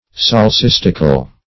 Meaning of solecistical. solecistical synonyms, pronunciation, spelling and more from Free Dictionary.
Search Result for " solecistical" : The Collaborative International Dictionary of English v.0.48: Solecistical \Sol`e*cis"tic*al\, a. Pertaining to, or involving, a solecism; incorrect.